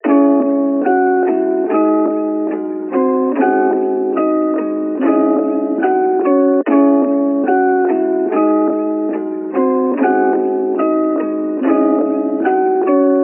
南方类型145BPM
Tag: 145 bpm Trap Loops Piano Loops 2.23 MB wav Key : F FL Studio